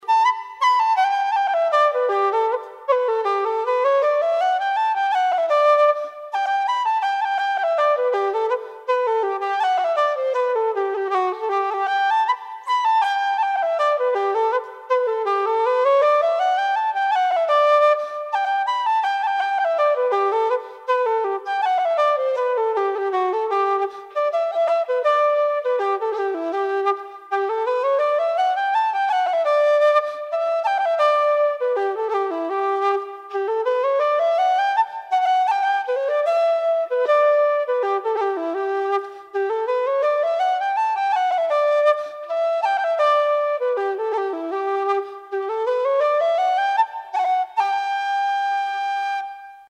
Traditional Irish Music -- Learning Resources Eddie Duffy's #2 (Barndance) /